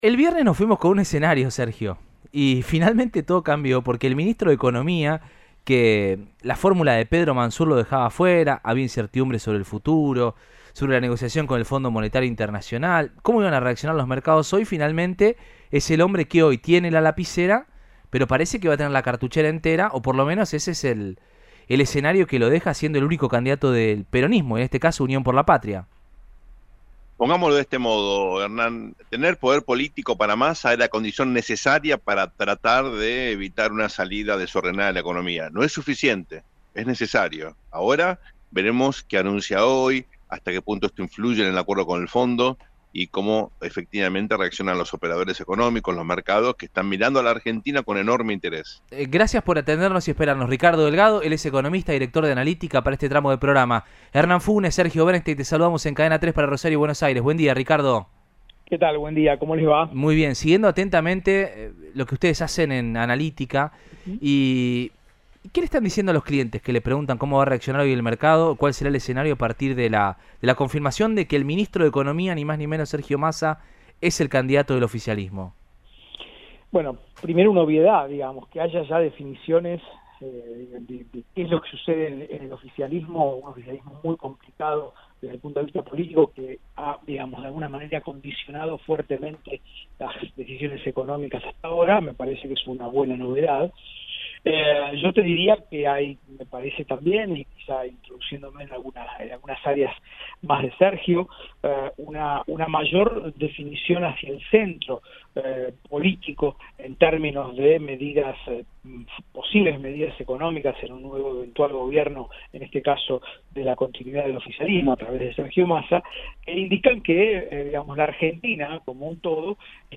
dialogó con Cadena 3 Rosario sobre la candidatura de Massa.